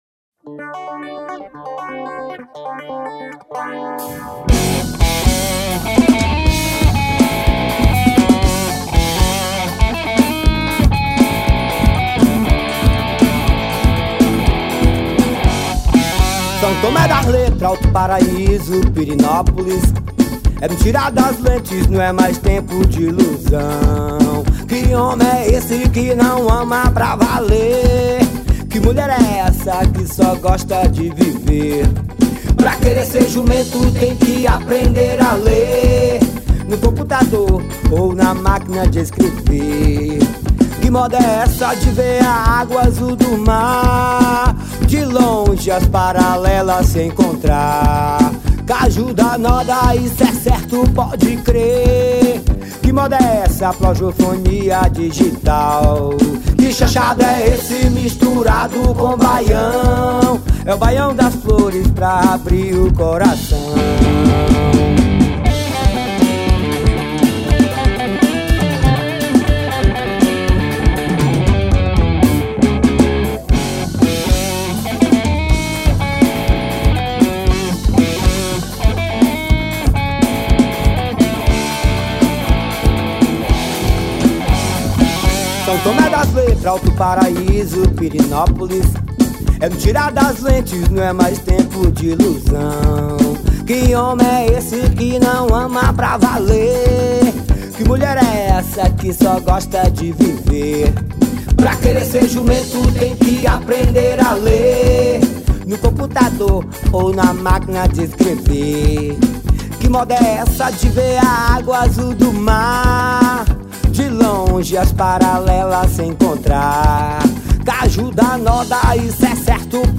2239   23:05:00   Faixa:     Forró